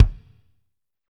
Index of /90_sSampleCDs/Northstar - Drumscapes Roland/DRM_Pop_Country/KIK_P_C Kicks x